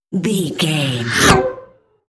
Sci fi vehicle pass by fast
Sound Effects
futuristic
pass by
vehicle